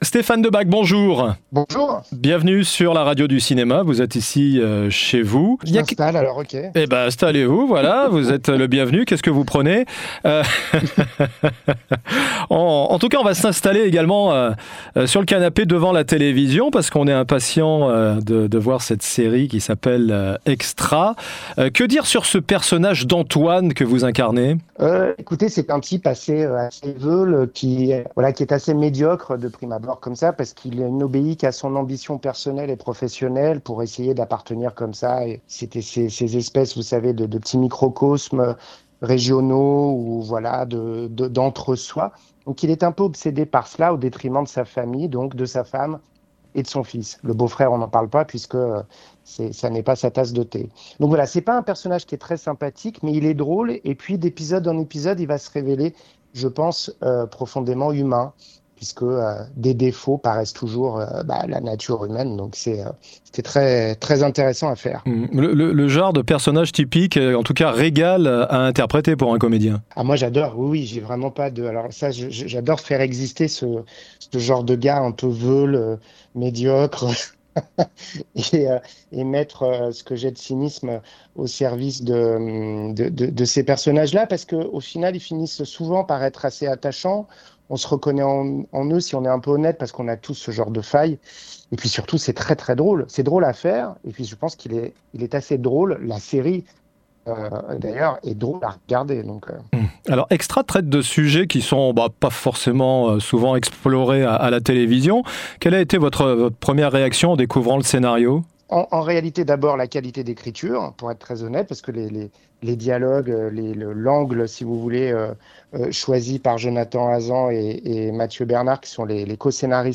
Interview: Stéphane Debac dans la série "Extra" : Un rôle marquant dans une comédie audacieuse